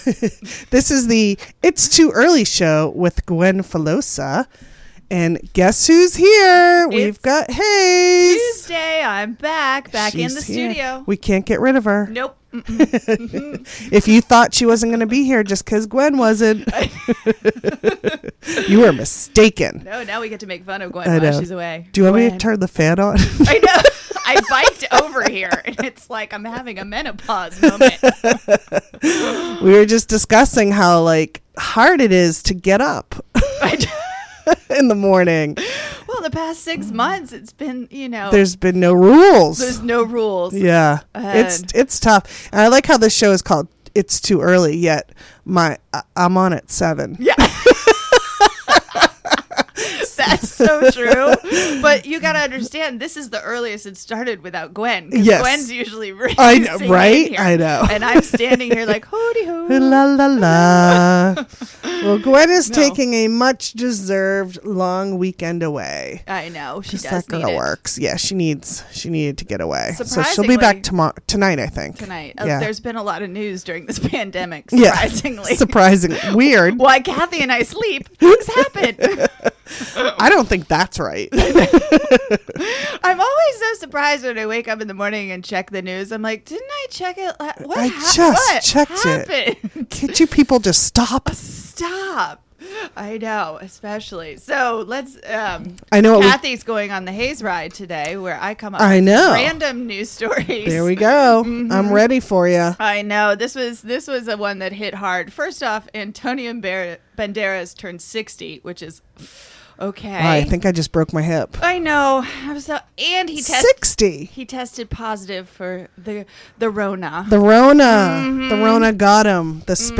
Lots of Laughs